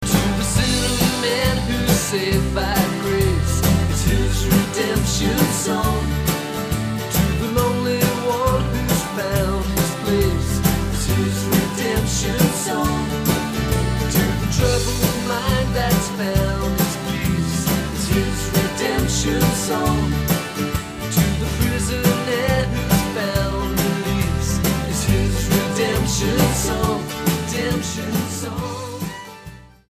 STYLE: Pop
FORMAT: CD Album